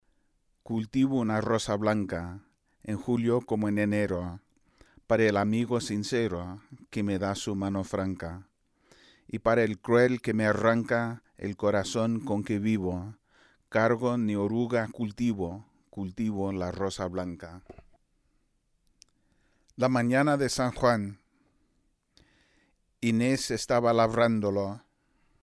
El ritmo en la poesía española.